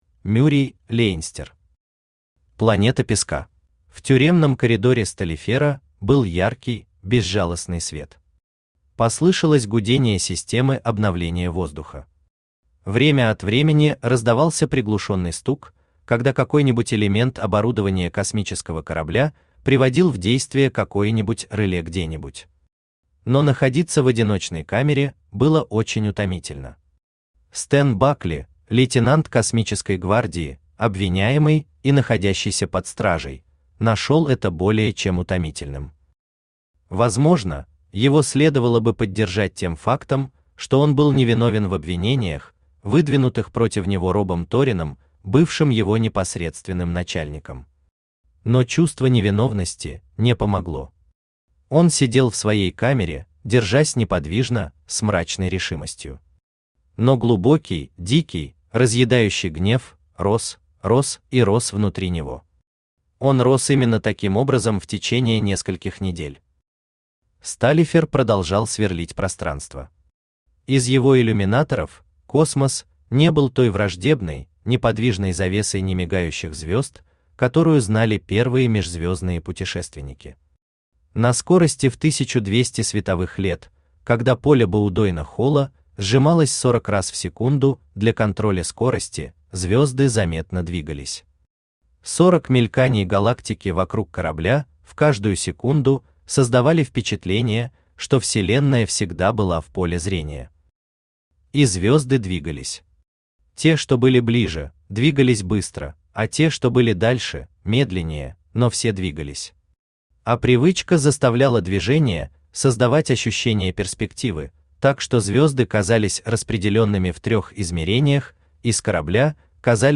Aудиокнига Планета песка Автор Мюрей Лейнстер Читает аудиокнигу Авточтец ЛитРес.